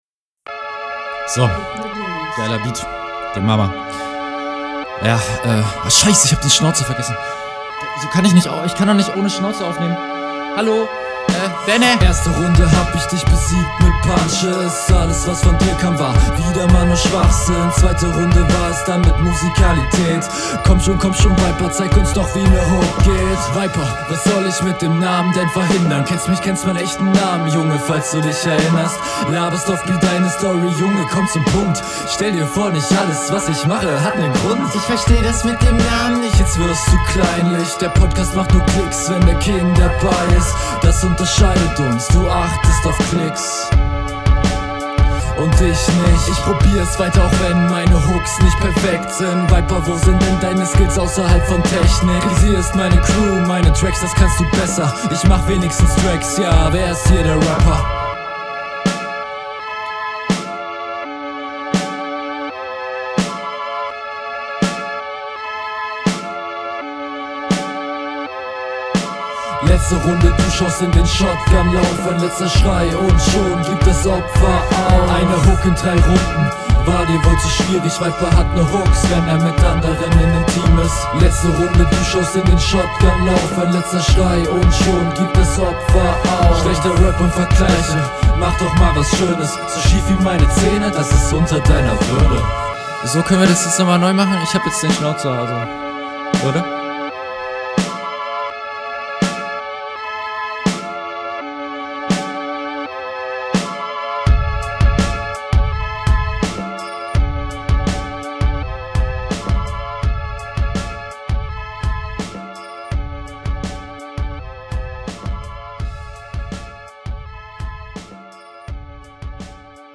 Find ich flowlich und auch stimmlich schwächer, das kommt einfach alles nicht so cool.